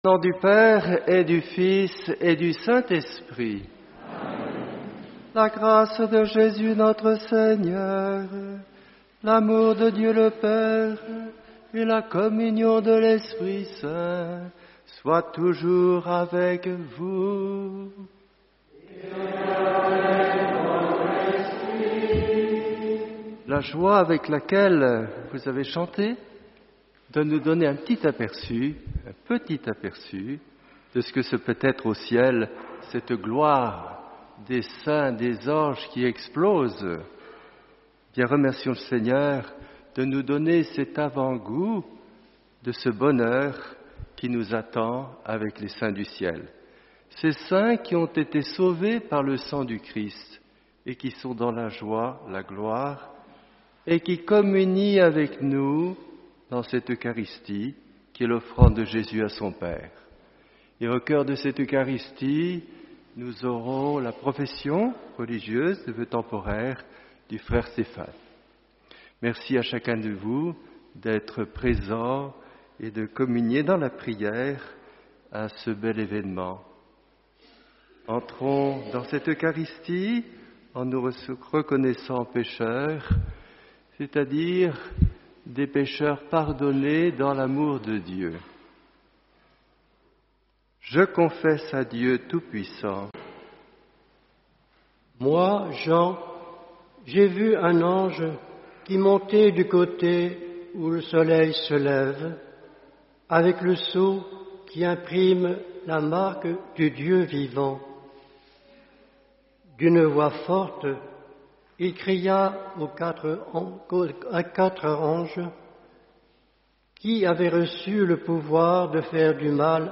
Homélie de la Solennité de TOUS LES SAINTS